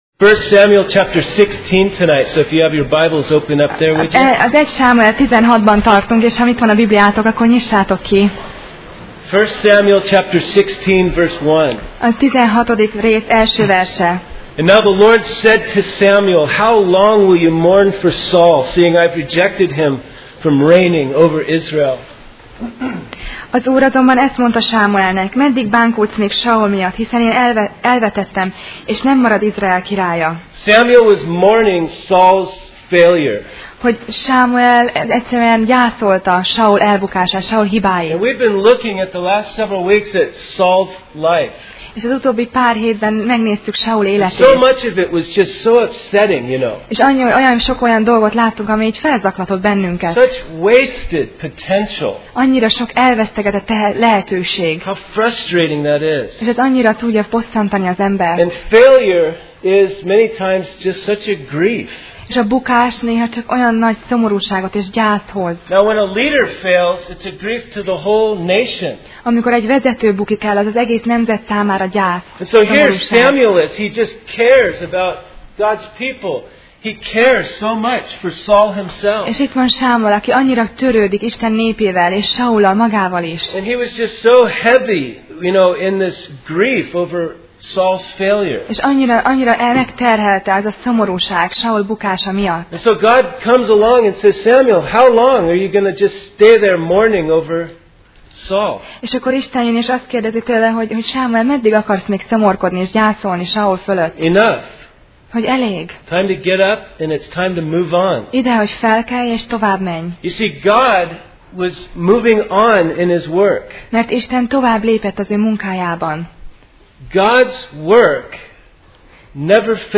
Alkalom: Szerda Este